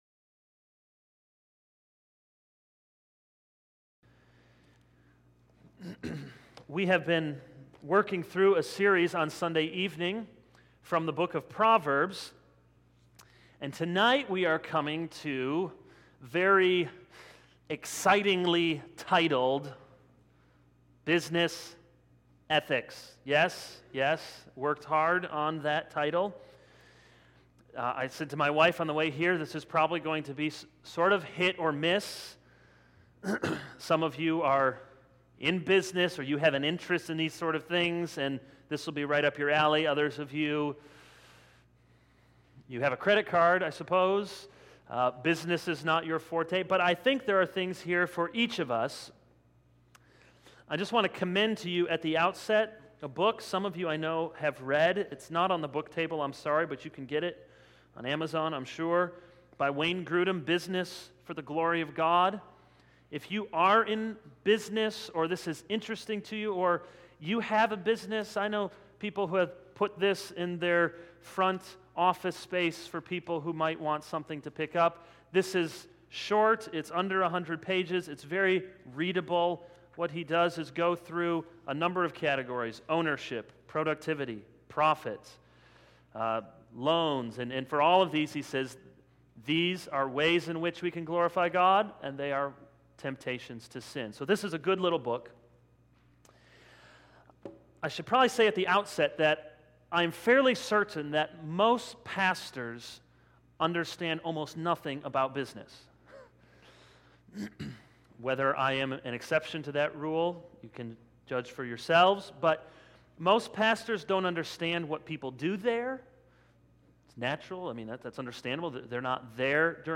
All Sermons A Study in Wise Words: Business Ethics 0:00 / Download Copied!